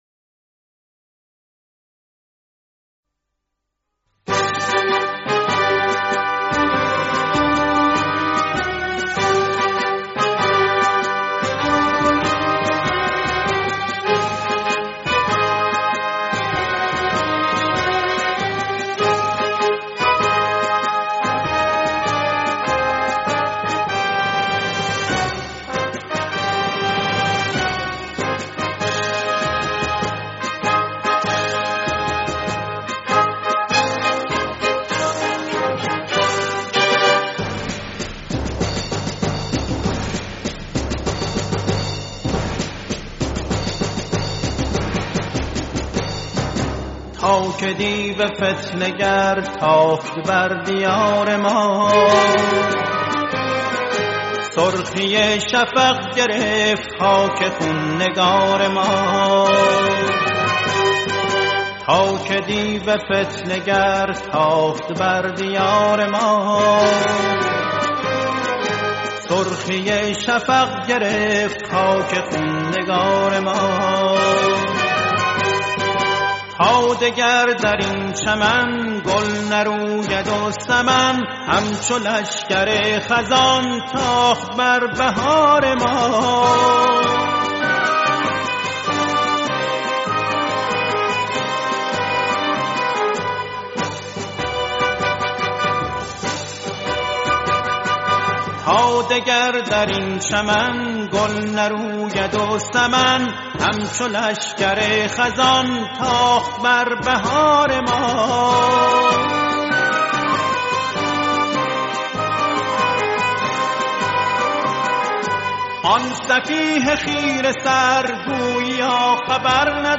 سرود چهارگاه